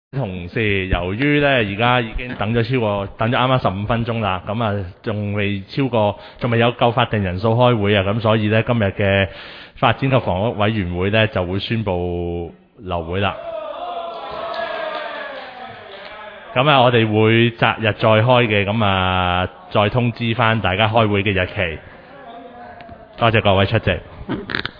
委员会会议的录音记录
发展及房屋委员会第五次 (因法定人数不足，会议未能召开)会议 日期: 2020-09-29 (星期二) 时间: 上午11时正 地点: 沙田区议会会议室 议程 讨论时间 因法定人数不足而休会 00:00:25 全部展开 全部收回 议程: 因法定人数不足而休会 讨论时间: 00:00:25 前一页 返回页首 如欲参阅以上文件所载档案较大的附件或受版权保护的附件，请向 区议会秘书处 或有关版权持有人（按情况）查询。